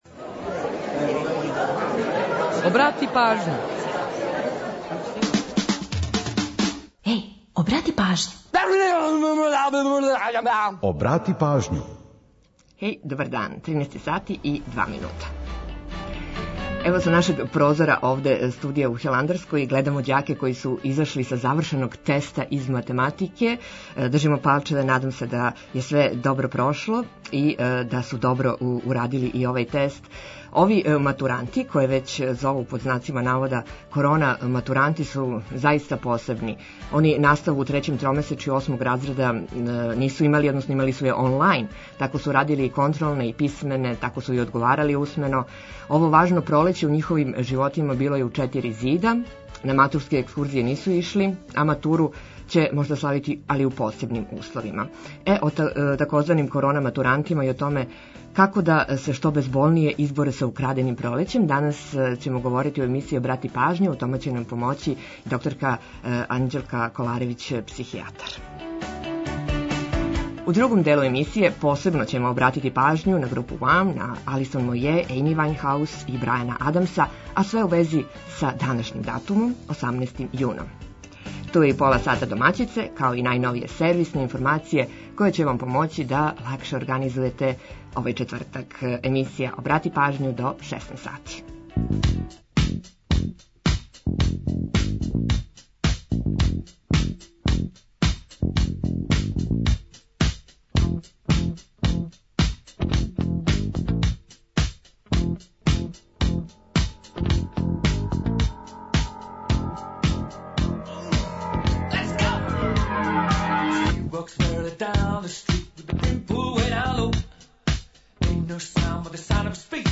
О тзв. „корона матурантима” и о томе како да се што безболније изборе са „украденим пролећем” разговарамо са психијатром